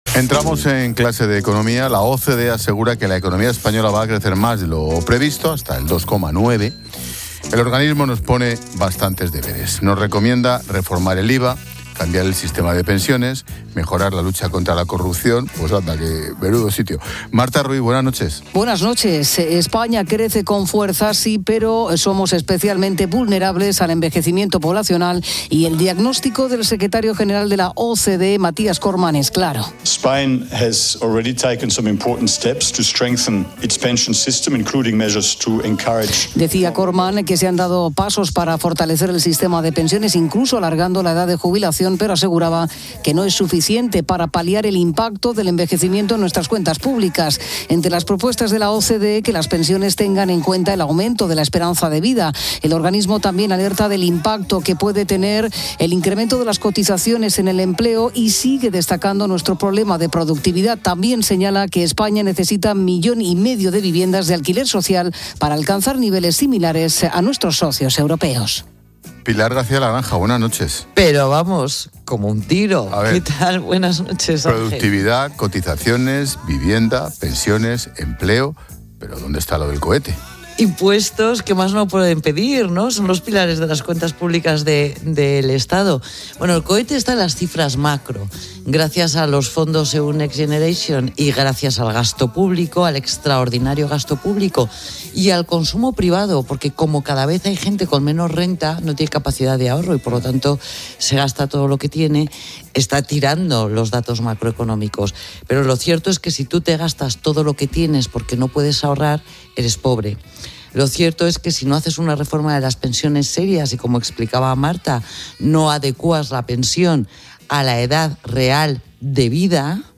Ángel Expósito y Pilar García de la Granja, experta económica y directora de Mediodía COPE, analizan en las Clases de Economía de La Linterna los deberes que pone la OCDE a España